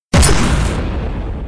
explosion 1